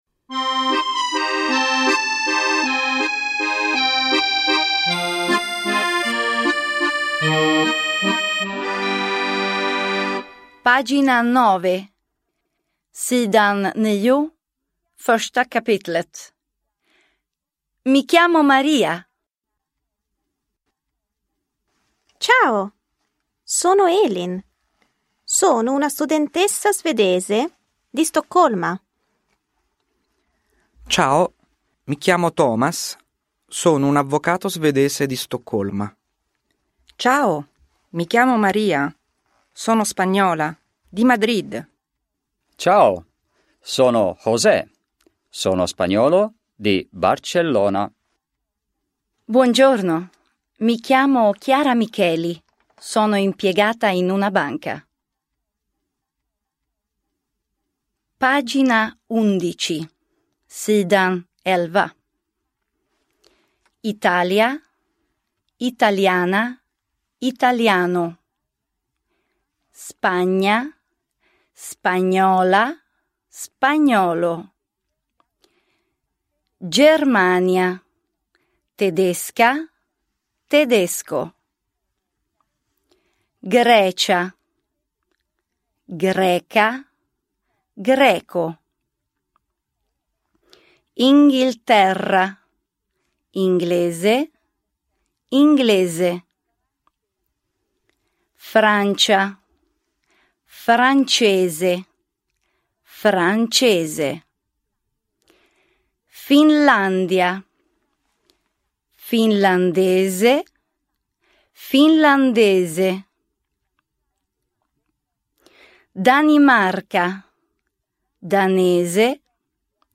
Ljudbok
Inlästa kapiteltexter till boken "Buon viaggio! 1".